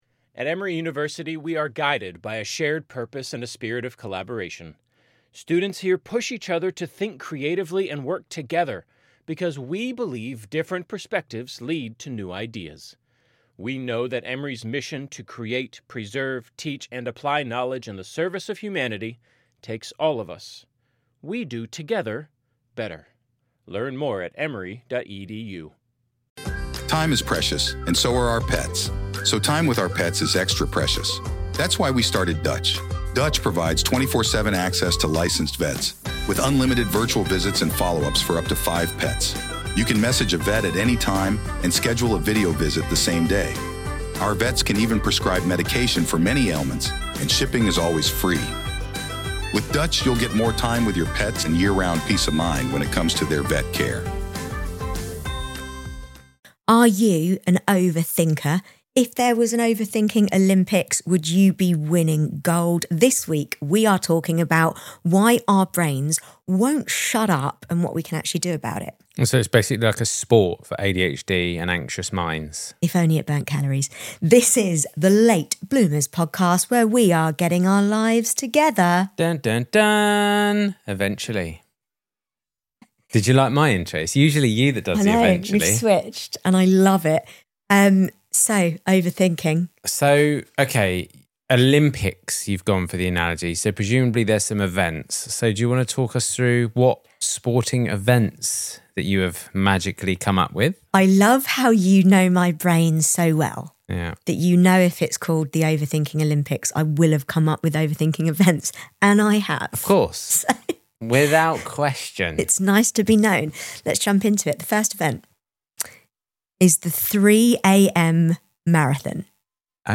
This is a funny, relatable, and surprisingly hopeful conversation for anyone whose brain won’t shut up.